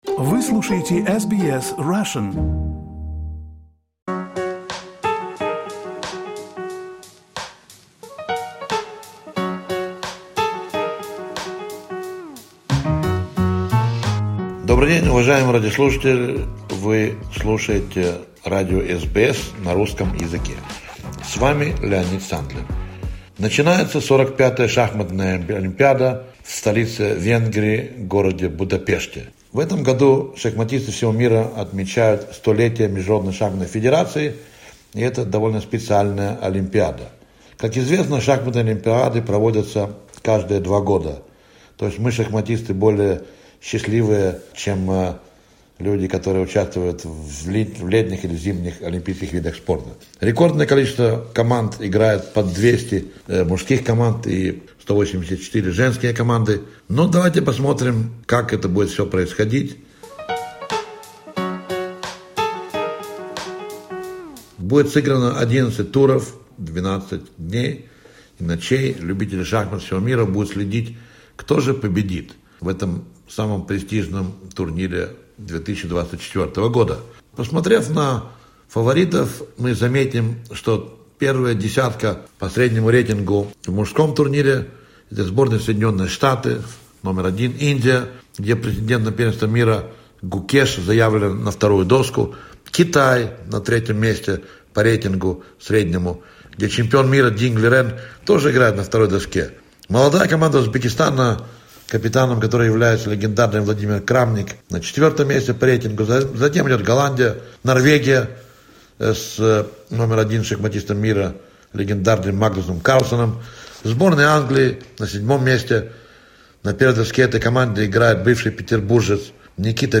Спортивный обзор.